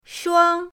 shuang1.mp3